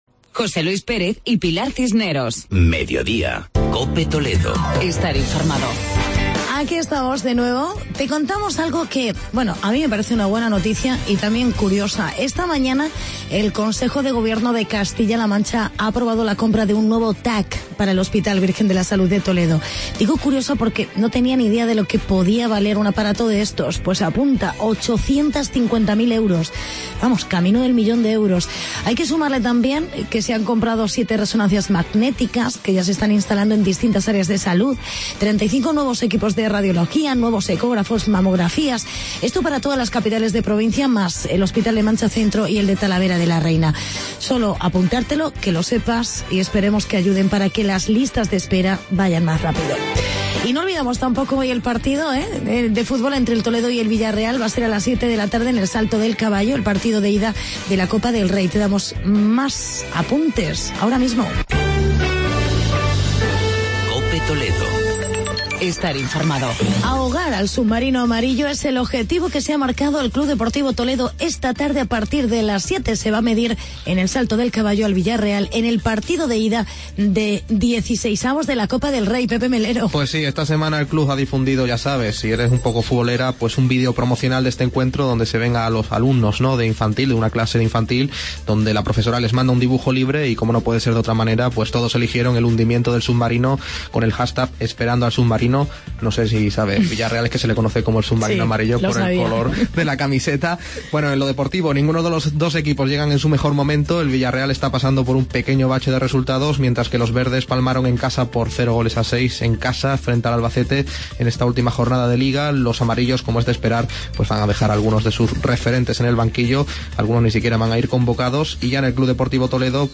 Actualidad y entrevista con el concejal Arturo Castillo sobre el calendario Ferial de Talavera de la Reina.